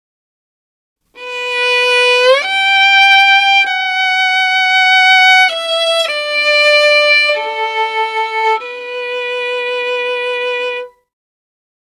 Saddest_Violin_2
cinema famous film funny hearts-and-flowers motif movie sad sound effect free sound royalty free Movies & TV